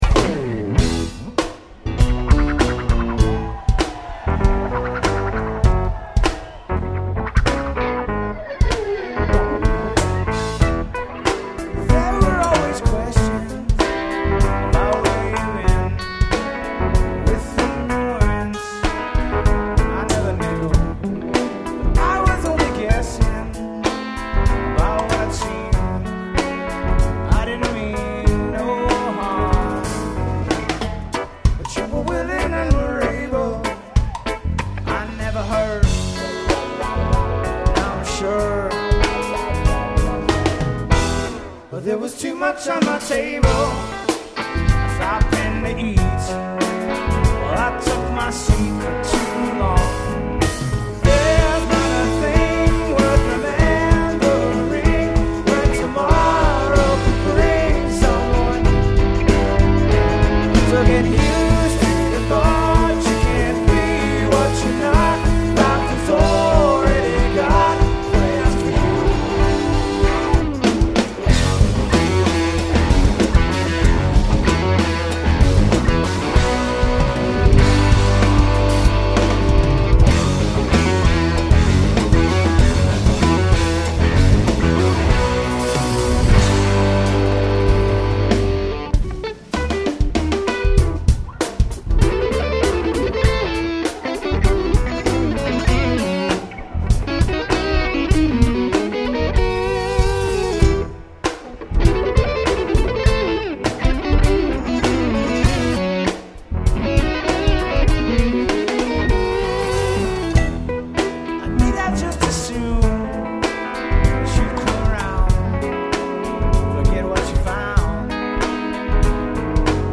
Liberty Hall - Lawrence, KS, USA